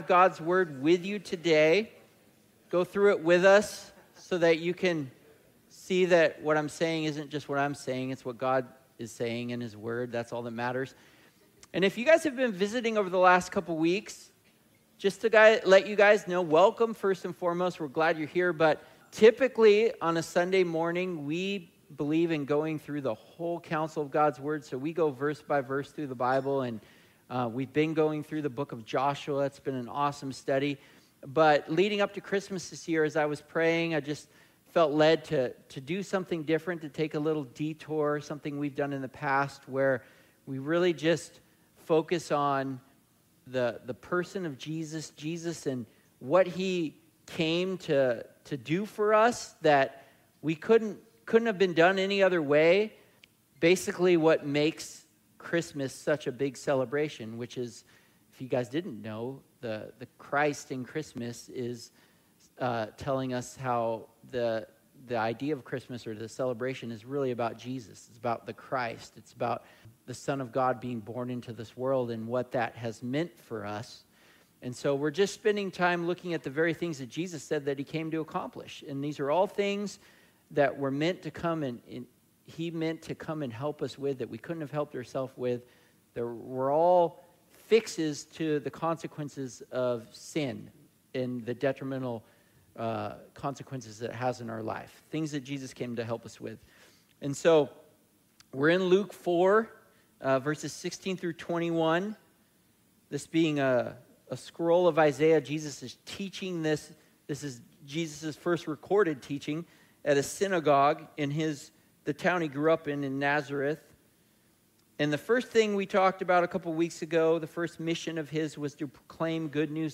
Sermons | Coastline Christian Fellowship